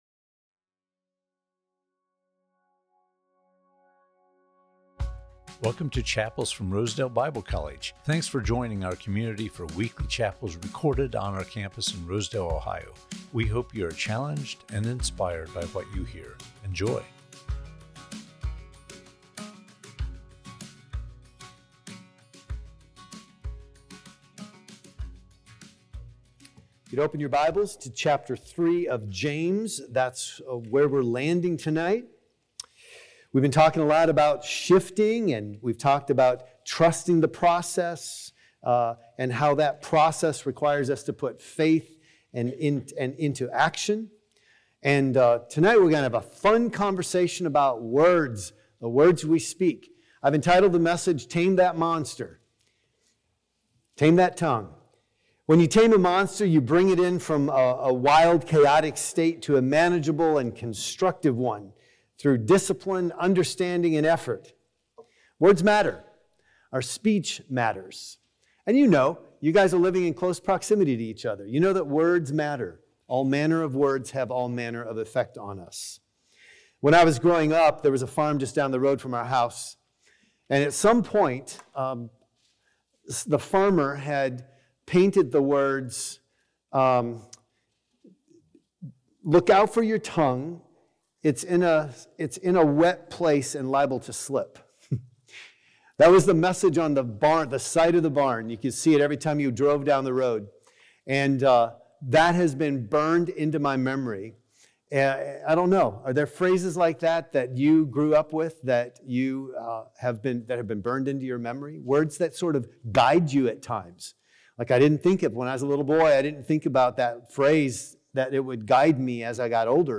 Chapels from Rosedale Bible College